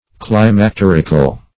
Climacterical \Clim`ac*ter"ic*al\